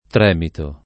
tremito [ tr $ mito ] s. m.